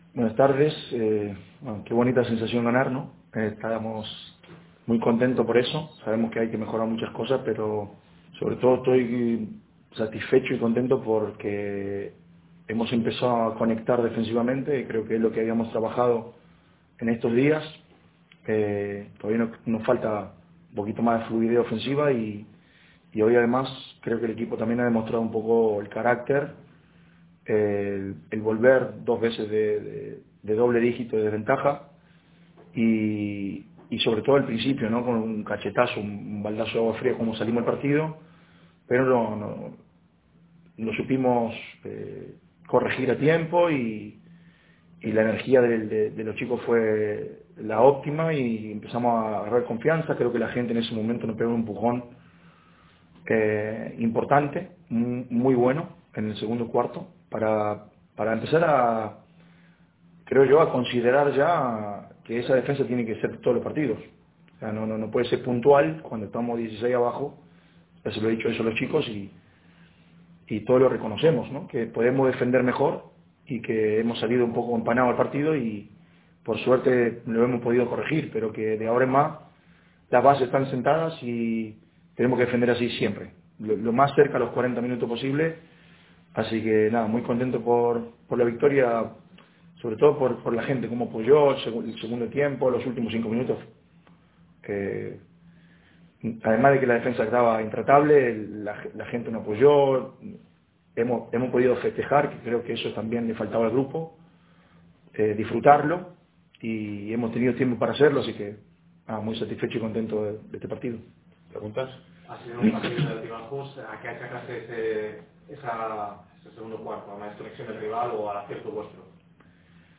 Declaraciones de Lucas Victoriano entrenador del Palmer Basket Mallorca Palma:
Lucas-Victoriano-post-Cartagena.mp3